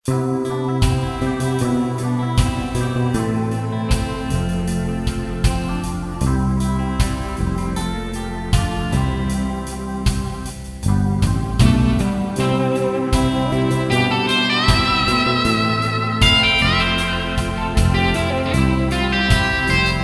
Reggae 70's